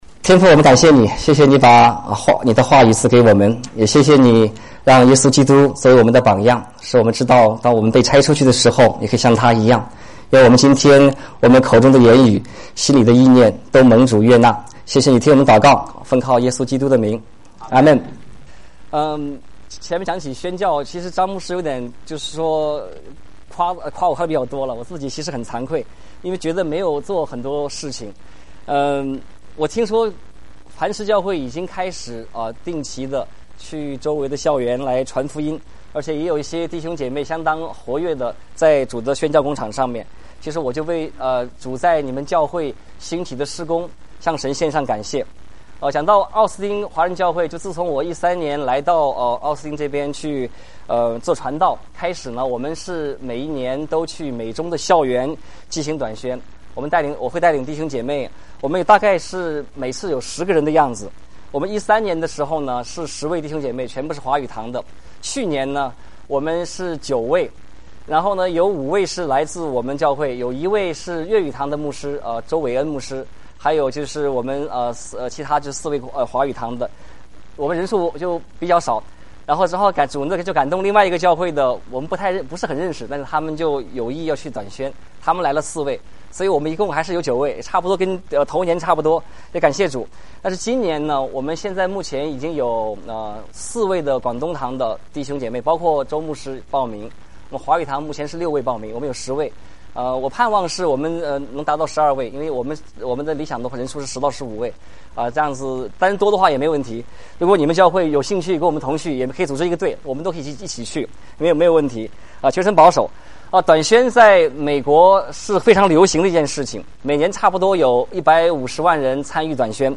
傳道 應用經文: 《 約翰福音》20:21-23